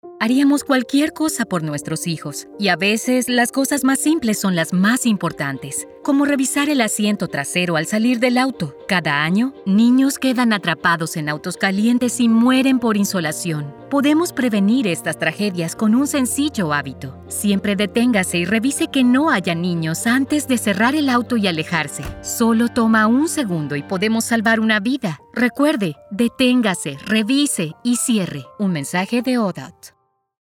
Child Heatstroke Radio PSA in Spanish
Child_Heatstroke_Stop_Look_Lock_Spanish.mp3